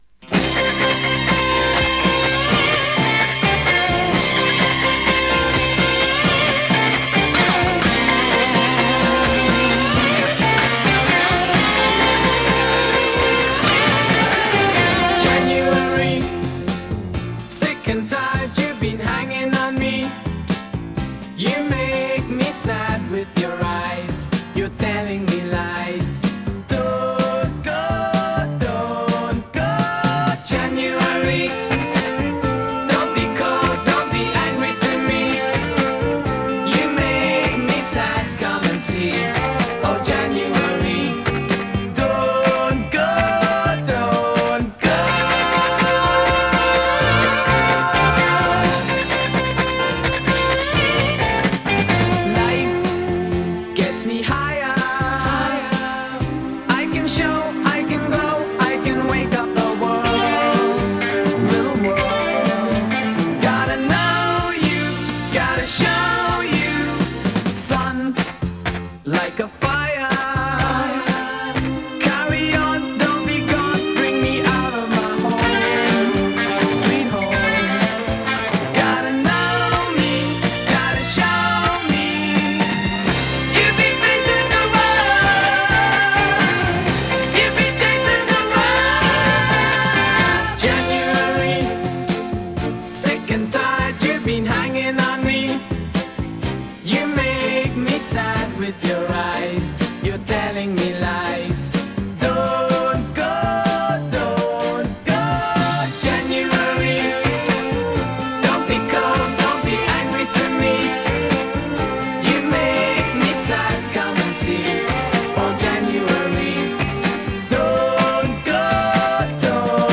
ブリティッシュ ポップ
ハーモニーポップ
エヴァーグリーンで甘酸っぱさ満点な70sポップ！